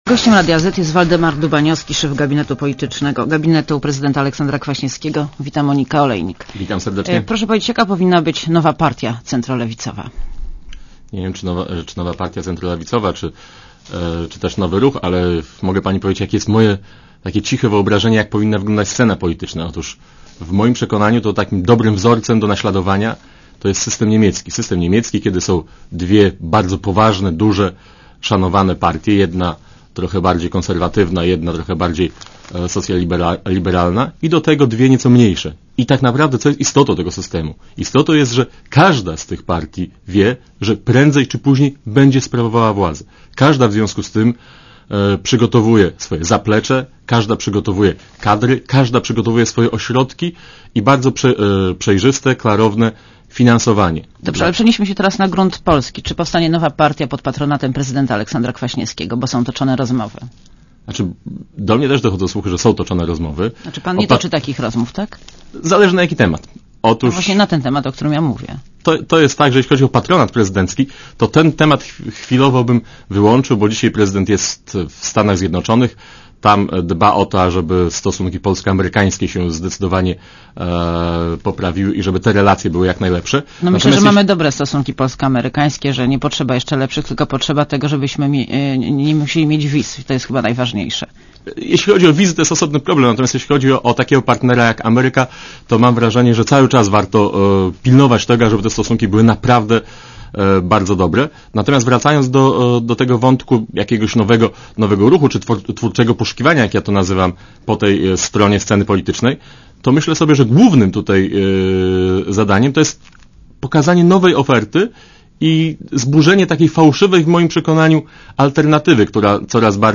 Posłuchaj wywiadu Gościem Radia Zet jest Waldemar Dubaniowski, szef gabinetu Prezydenta Aleksandra Kwaśniewskiego.